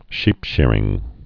(shēpshîrĭng)